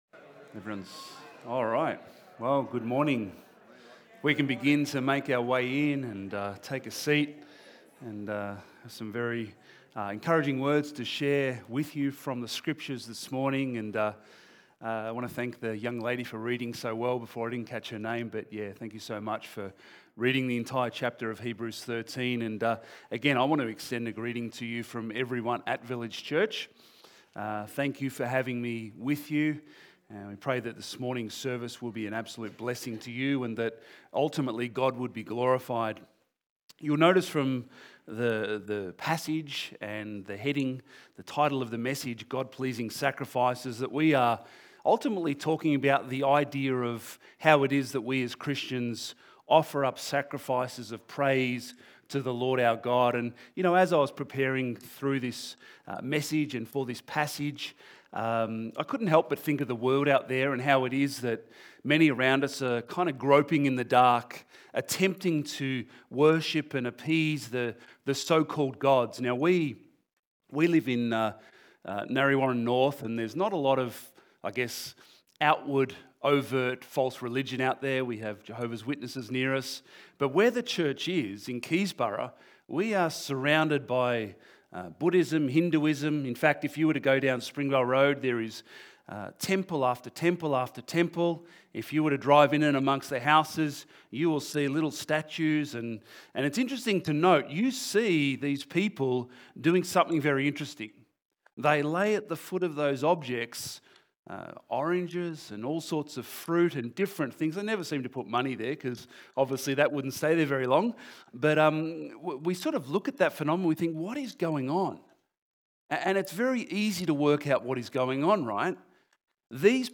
Church-Sermon-310825.mp3